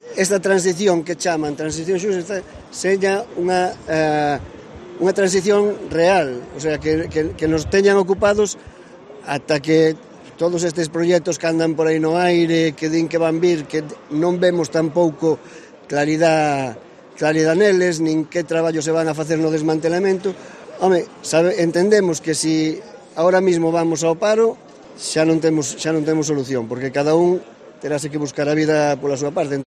Concentración de los trabajadores de Endesa en la plaza de España de Ferrol